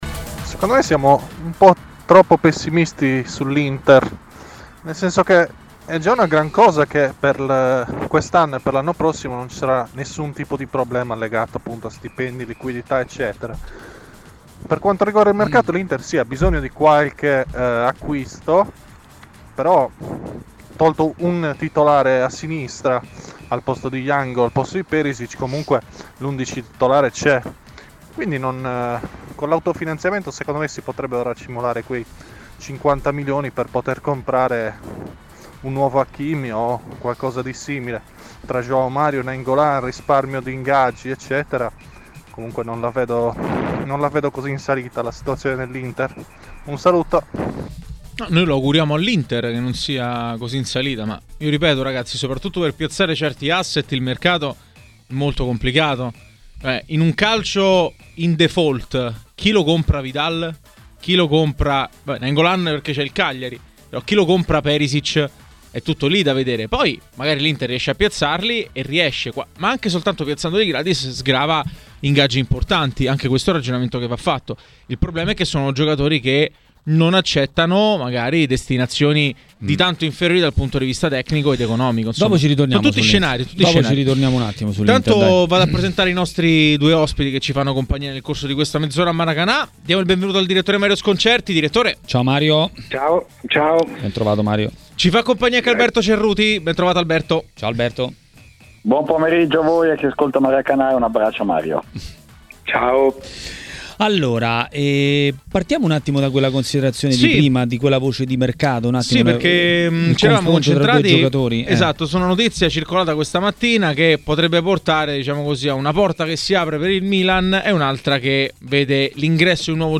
Il direttore Mario Sconcerti a TMW Radio, durante Maracanà, ha parlato dei temi dell'ultimo turno di campionato e non solo.